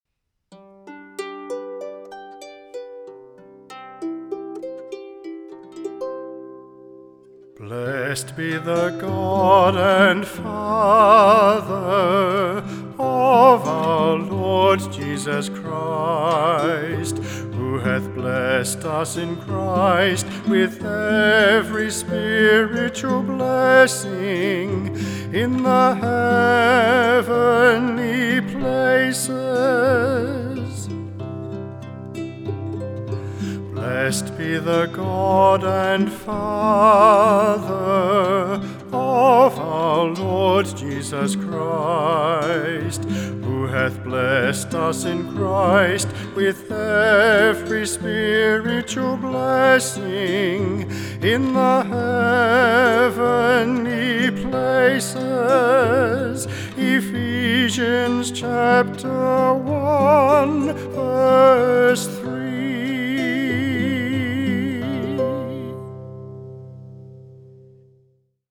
Vocalist
Harp
Guitar